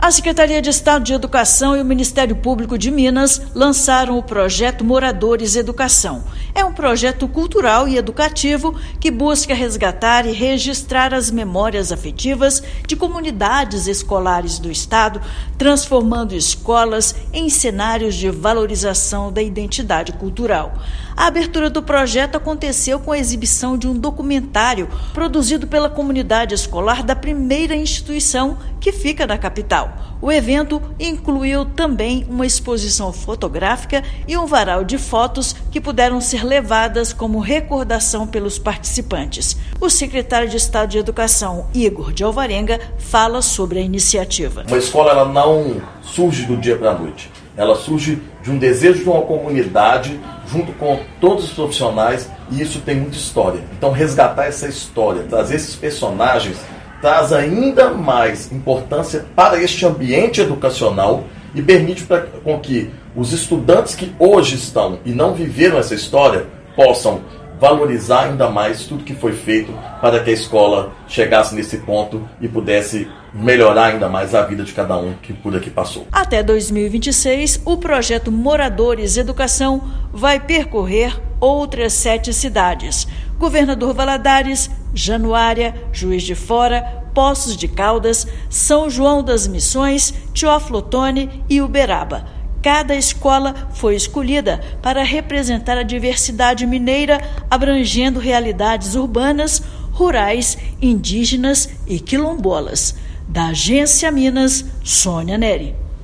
Iniciativa resgata memórias afetivas de estudantes, professores e moradores, destacando a escola como território de pertencimento e patrimônio cultural. Ouça matéria de rádio.